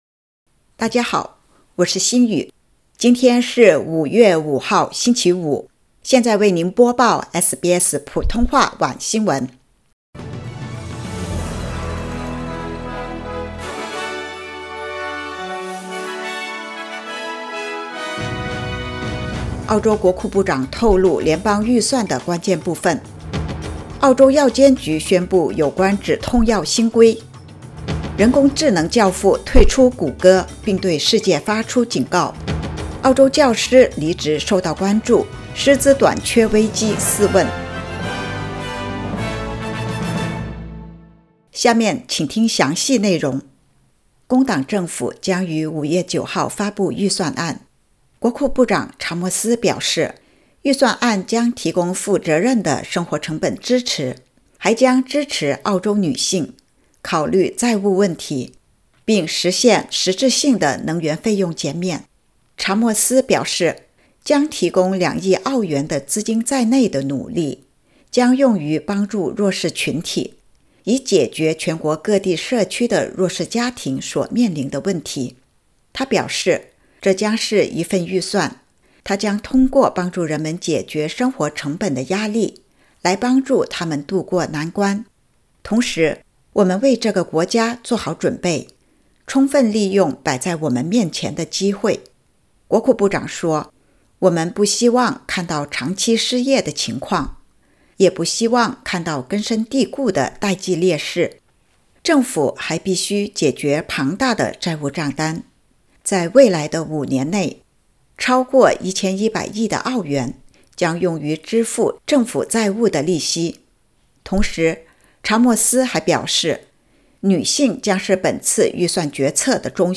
SBS晚新闻（2023年5月5日）
SBS Mandarin evening news Source: Getty / Getty Images